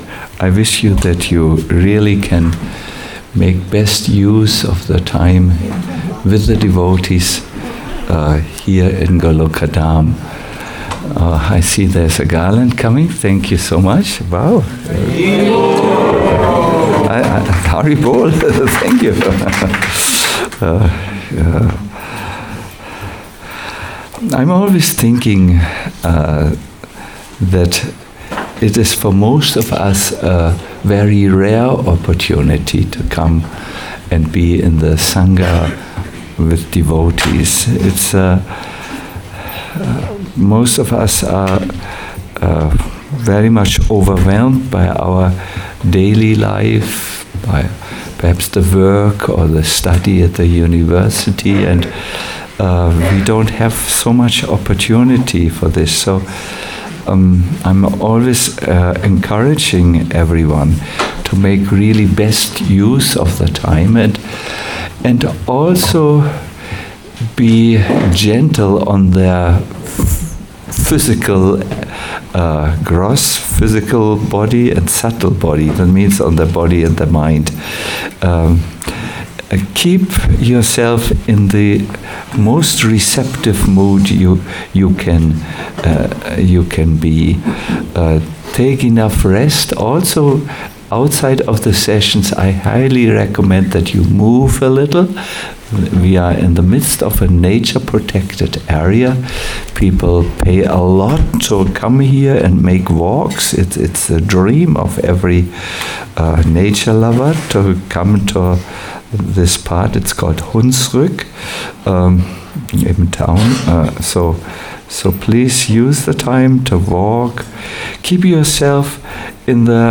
Bhakti Immersion 2 - The Progressive Path - a lecture